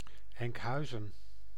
Enkhuizen (Dutch pronunciation: [ɛŋkˈɦœyzə(n)]
Nl-Enkhuizen.ogg.mp3